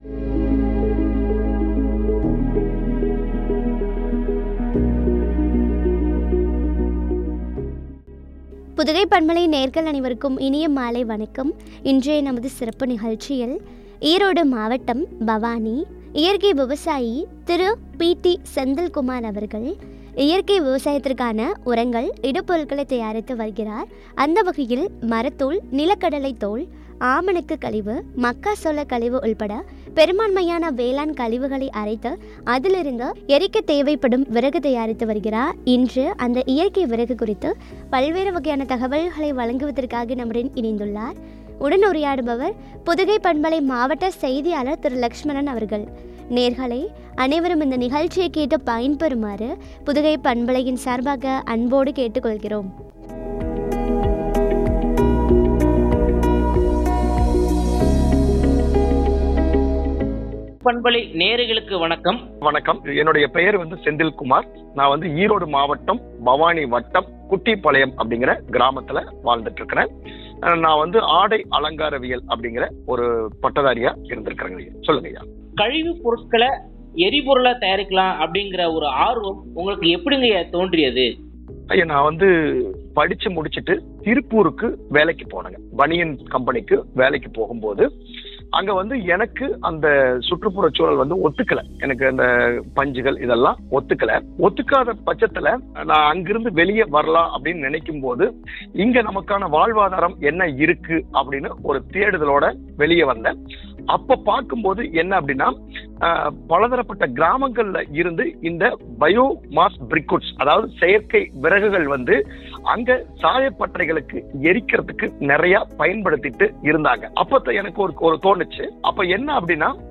” இயற்கை மாற்று எரிபொருள்” குறித்து வழங்க உரையாடல்.